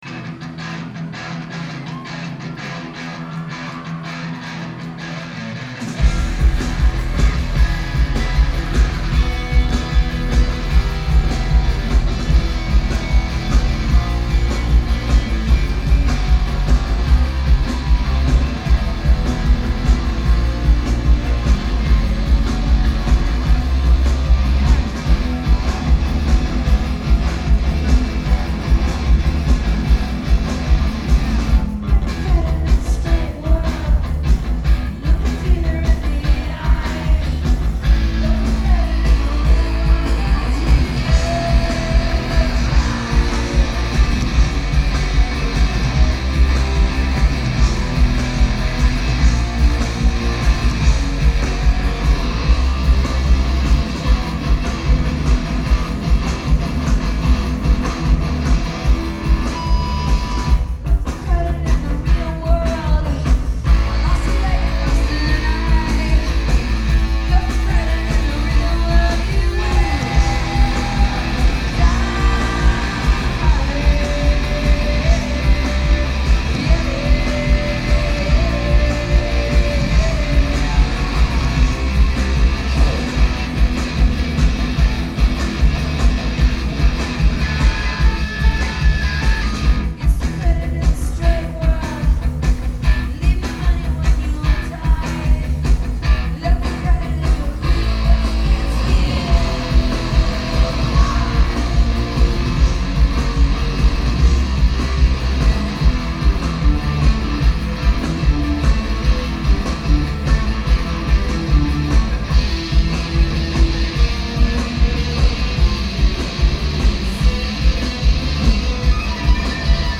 live 1993-1995
Orpheum, Boston, MA